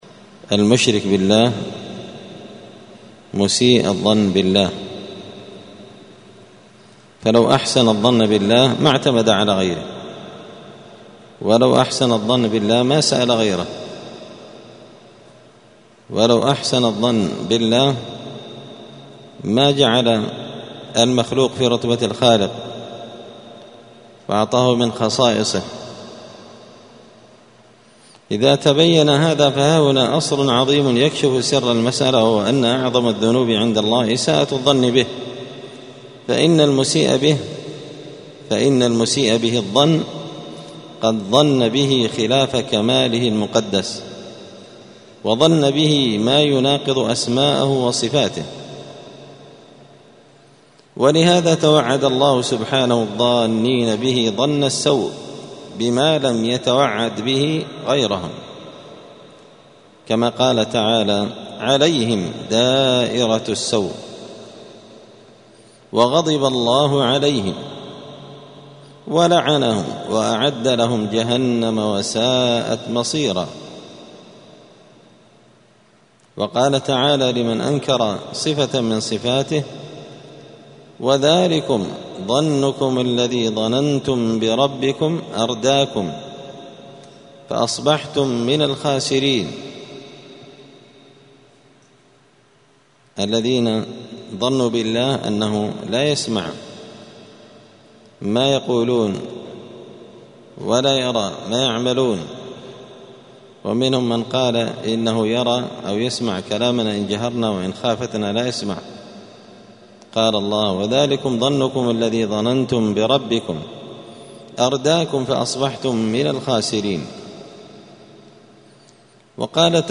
*الدرس الثاني والستون (62) فصل سوء الظن بالله*